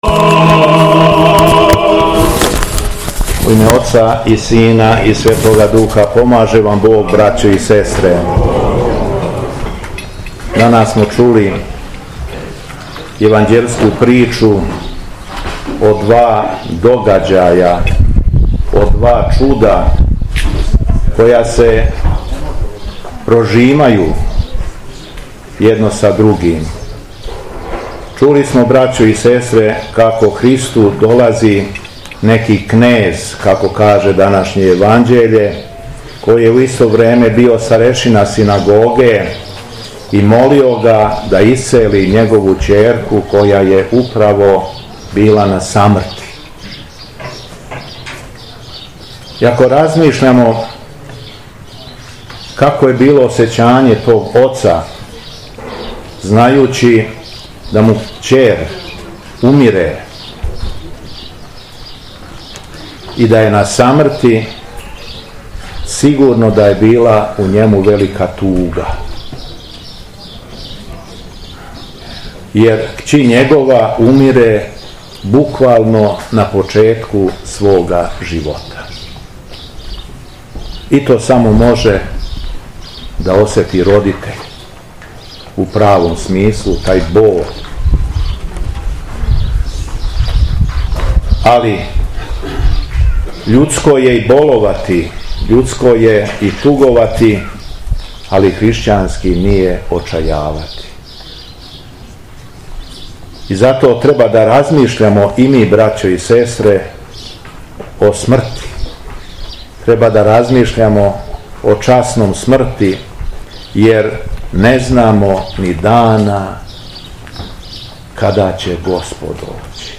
Беседа Његовог Високопреосвештенства Митрополита шумадијског г. Јована
Верном народу митрополит се обратио Богонадахнутом беседом: